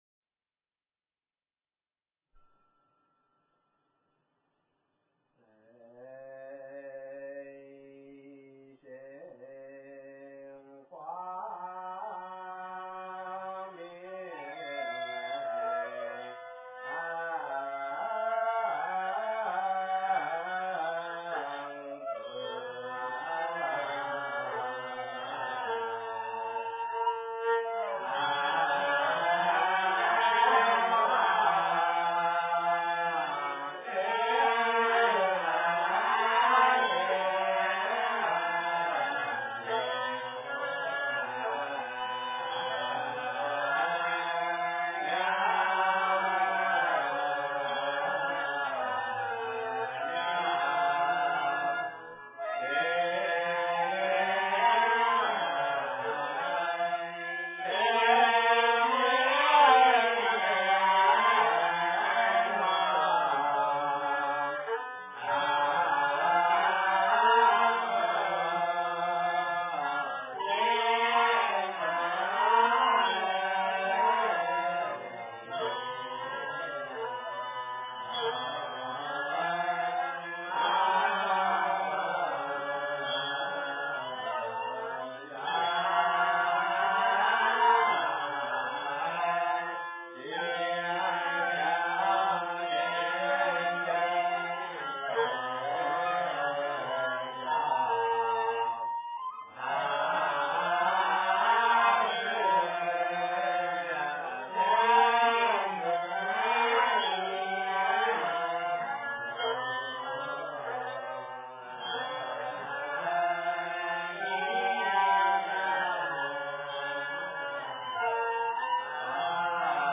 最盛光明自在王--僧团 经忏 最盛光明自在王--僧团 点我： 标签: 佛音 经忏 佛教音乐 返回列表 上一篇： 大悲咒+伽蓝赞--僧团 下一篇： 普佛(代晚课)(下)--僧团 相关文章 楞严咒 (二)--圆光佛学院众法师 楞严咒 (二)--圆光佛学院众法师...